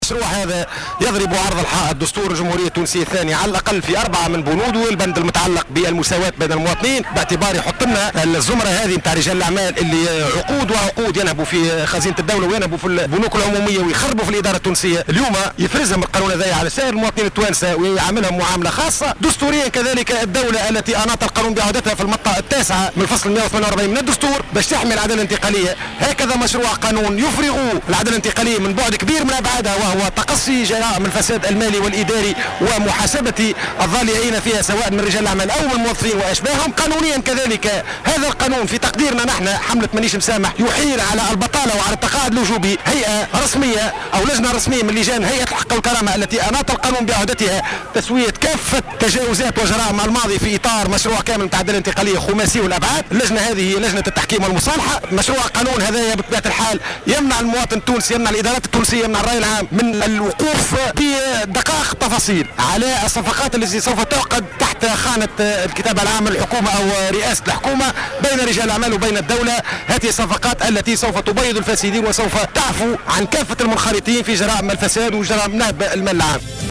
خلال مشاركته في الوقفة الاحتجاجية ضد مشروع قانون المصالحة بالمهدية